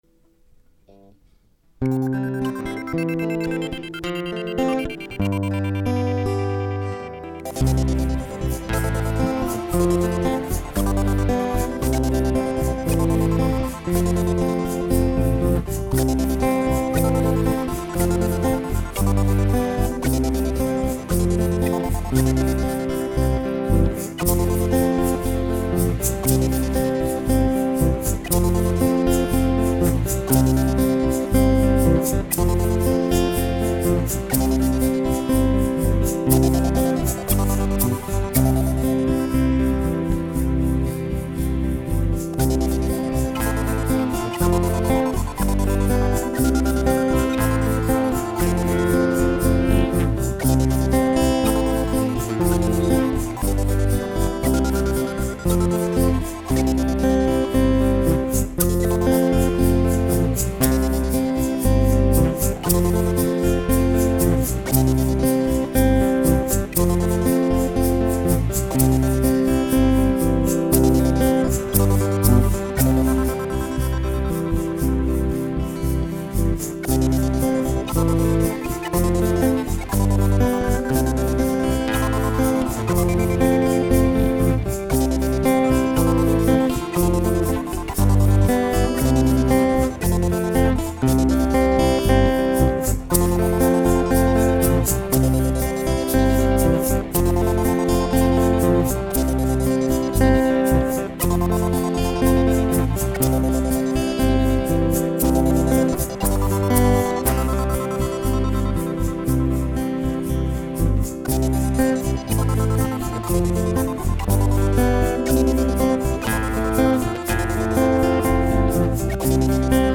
Instrumental Only